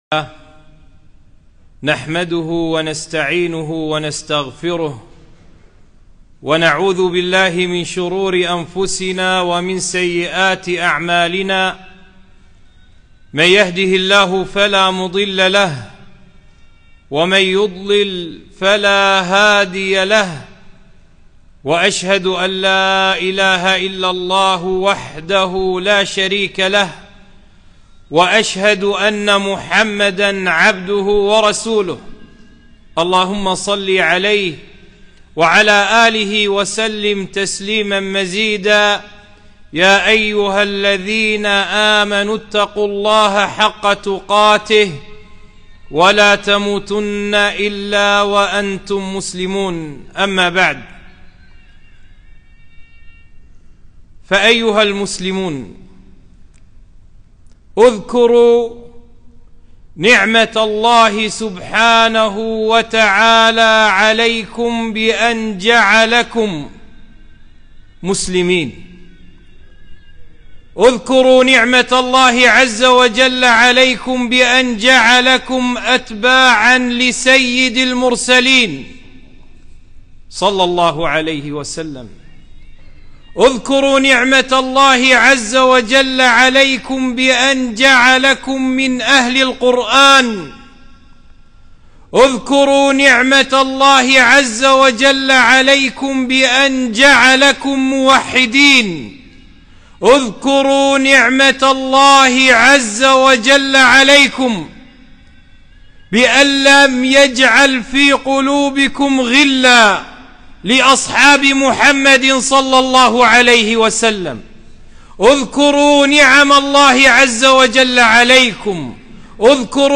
خطبة - فاذكروا آلاء الله